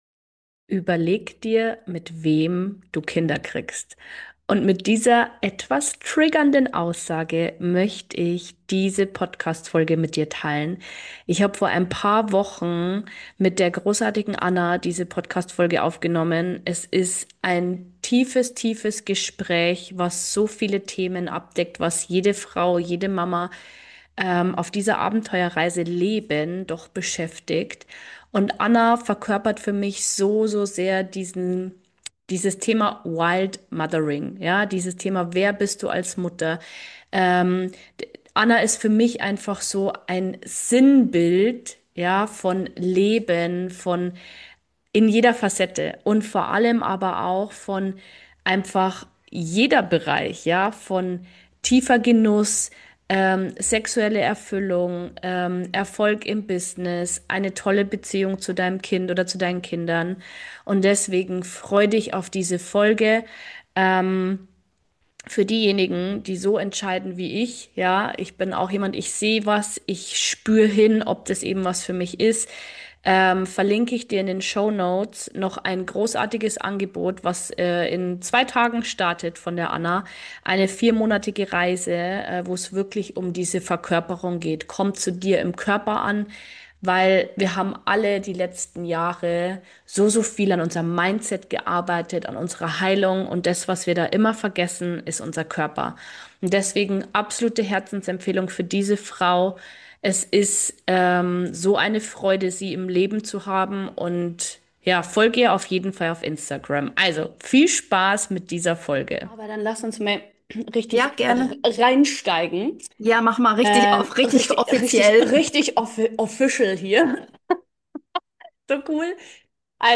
Ein ehrlicher, ungeschönter Deep Talk über Mutterschaft, Beziehungen und die Freiheit, sich neu zu erfinden.